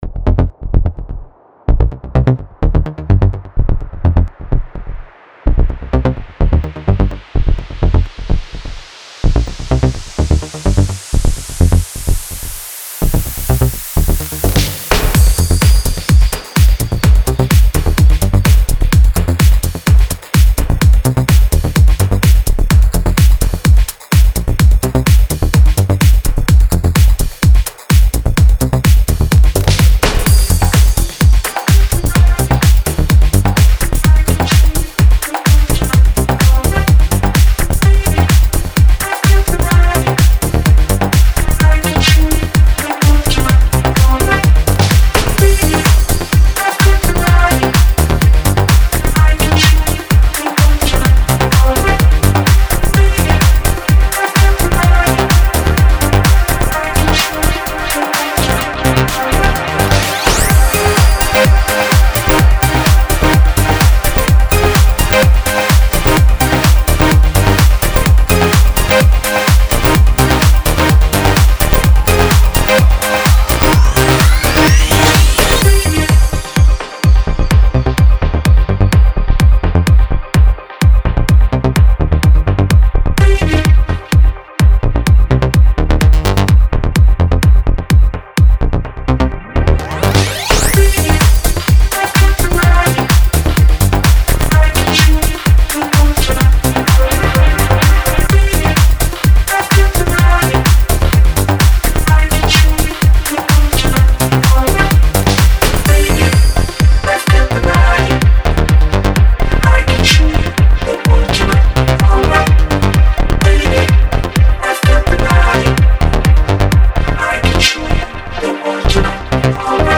Minimal, Electronic, Jazz, Tech, Vintage, Oldschool - House